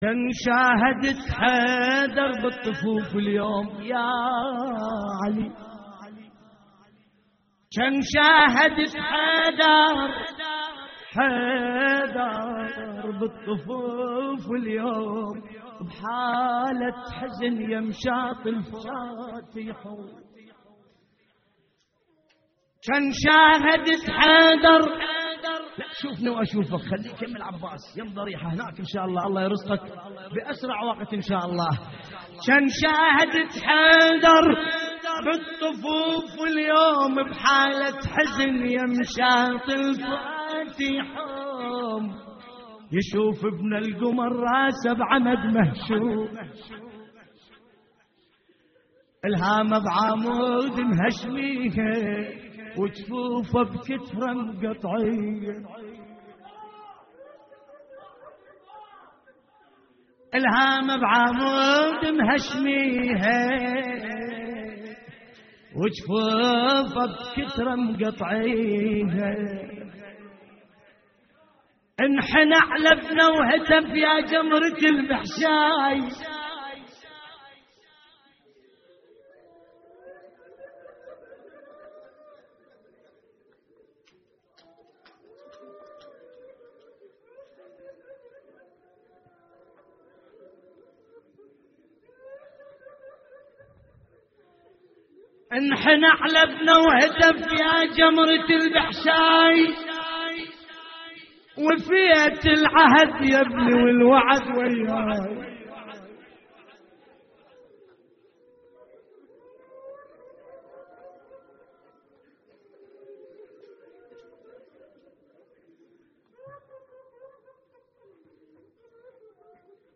نعي لحفظ الملف في مجلد خاص اضغط بالزر الأيمن هنا ثم اختر